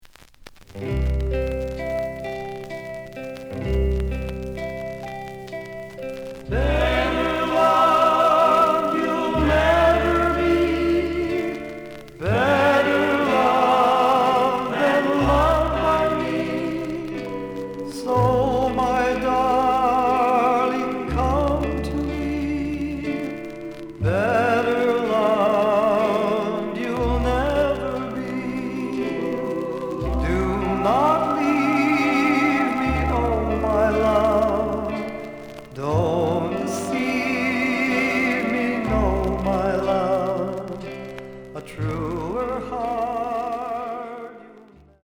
The listen sample is recorded from the actual item.
●Genre: Rhythm And Blues / Rock 'n' Roll
Some noise on both sides.